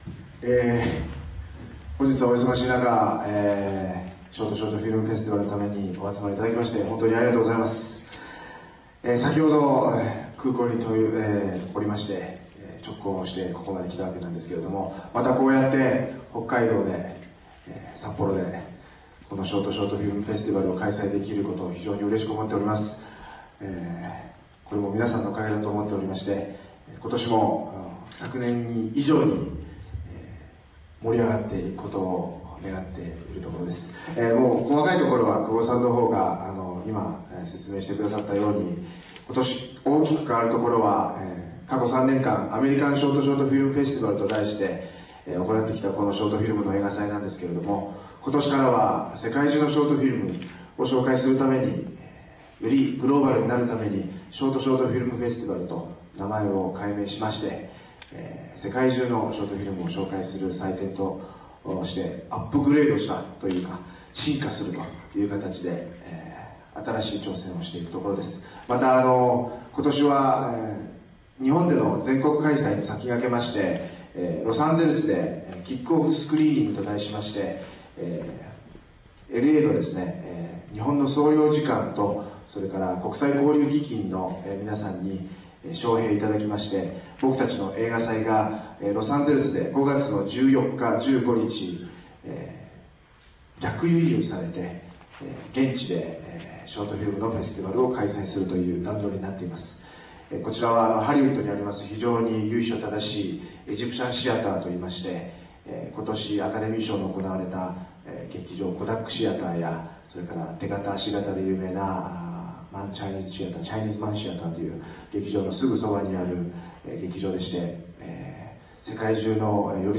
ショート・ショート・フィルム フェスティバル2002札幌・試写会が、4月24日にアーバンホール(札幌市中央区南3西5、アーバン札幌7階)で開かれた。